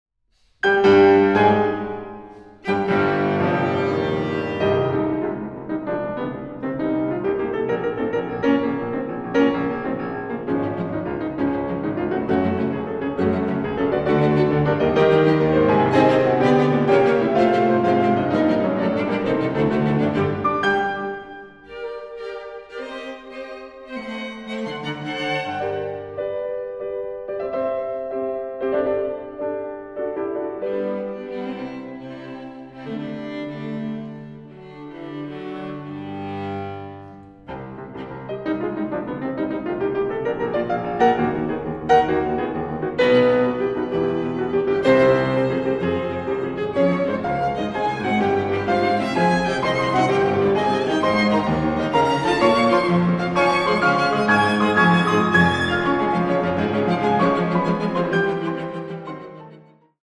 Klavierquartett